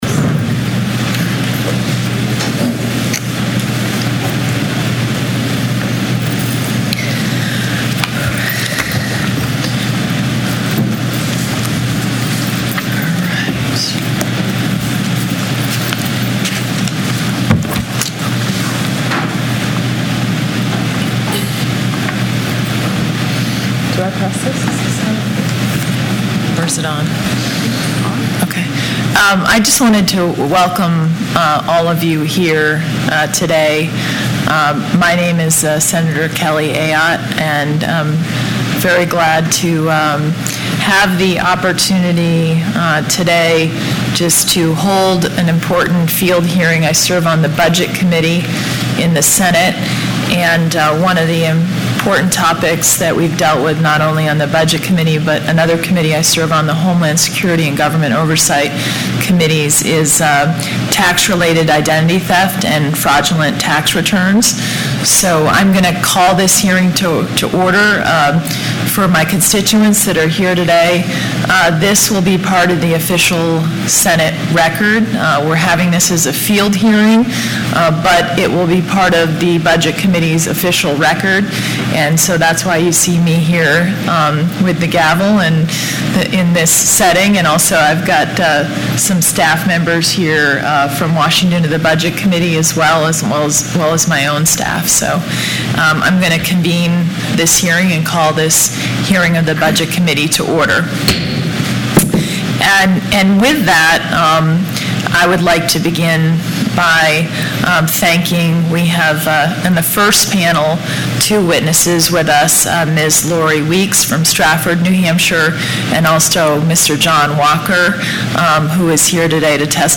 nh-field-hearing_-audio-part-1&download=1